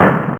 1 channel
Percu17L.wav